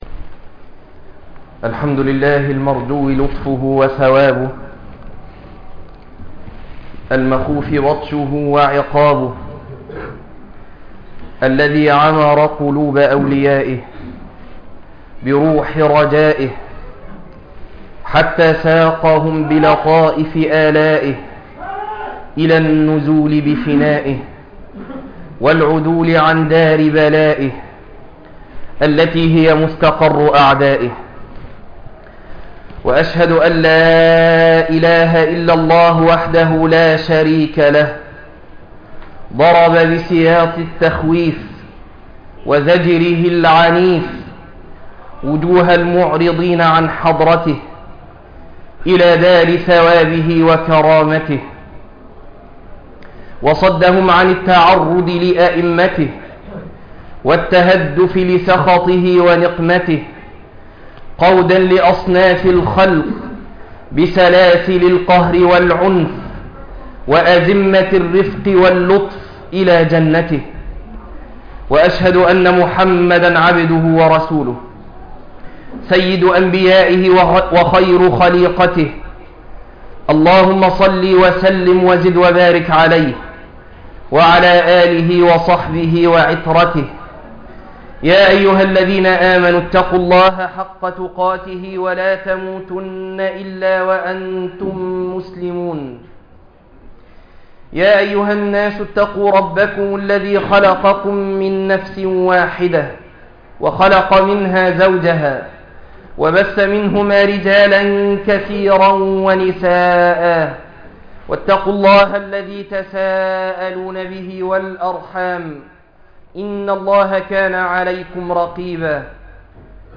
الخوف من الله - خطبة